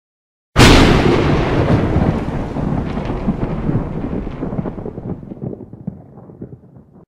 Lightening Sound Effect Free Download
Lightening